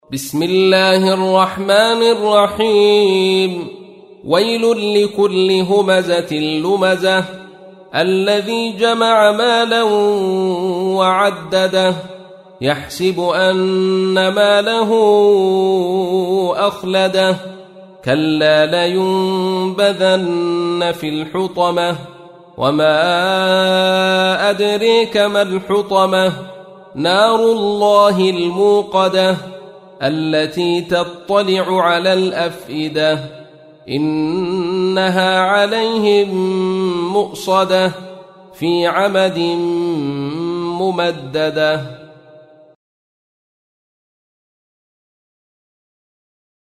104. سورة الهمزة / القارئ